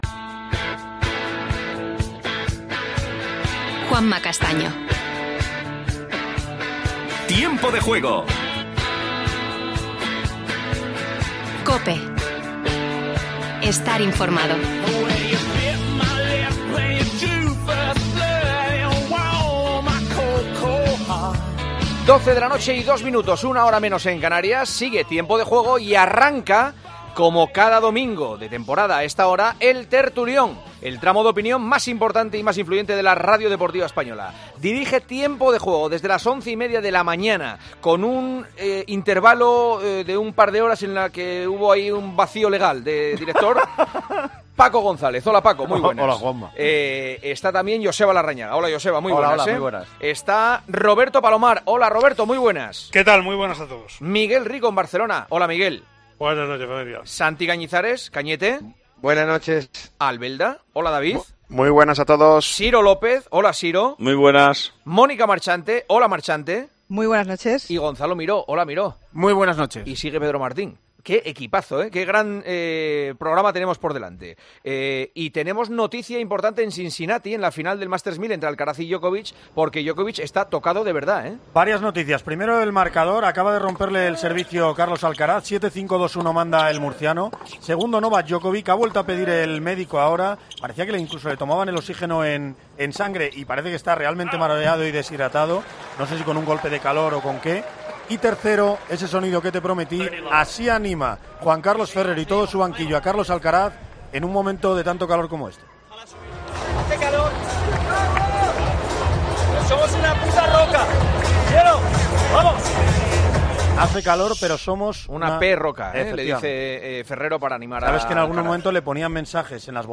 Gènere radiofònic Esportiu Presentador/a Castaño, Juanma